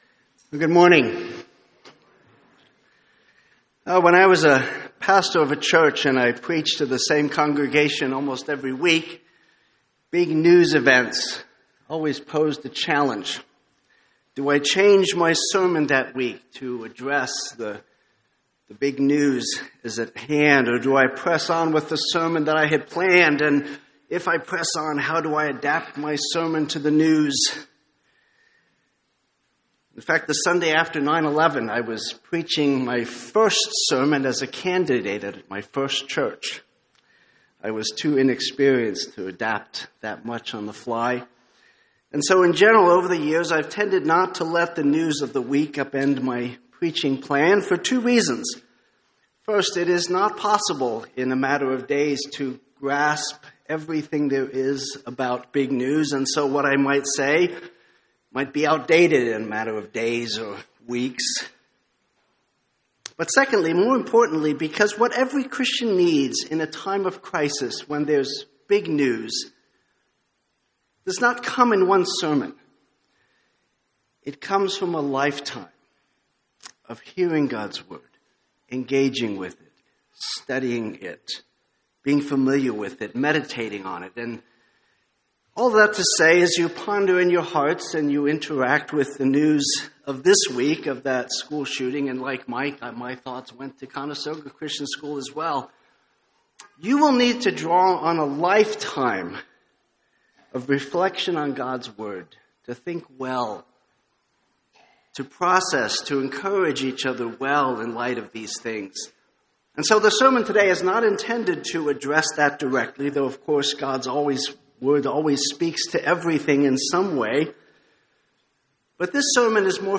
Sermons on Psalm 126 — Audio Sermons — Brick Lane Community Church